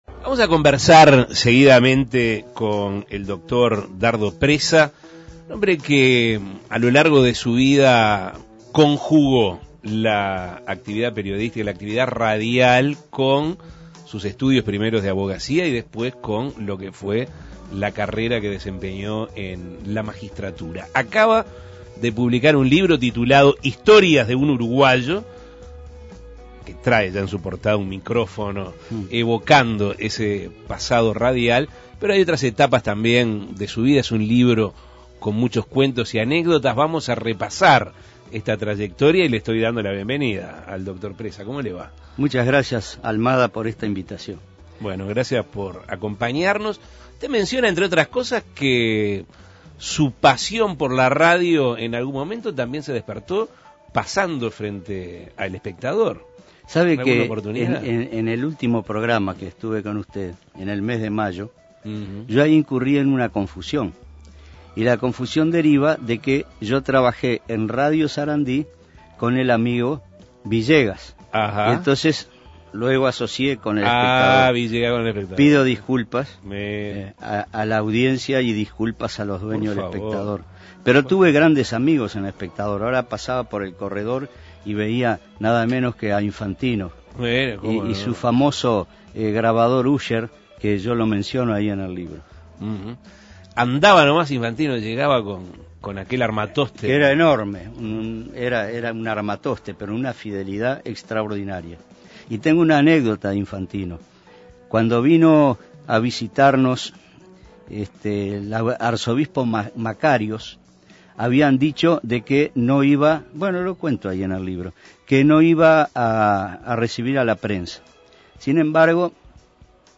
Entrevistas "Historias de un uruguayo" Imprimir A- A A+ Asuntos Pendientes entrevistó al Dr. Dardo Preza, quien acaba de lanzar un libro titulado "Historias de un uruguayo".